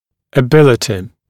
[ə’bɪlətɪ][э’билэти]способность